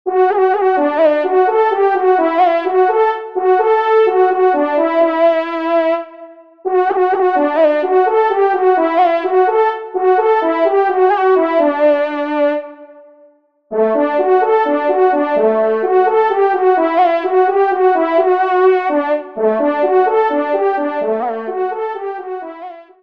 Solo Trompe      (Ton de vénerie)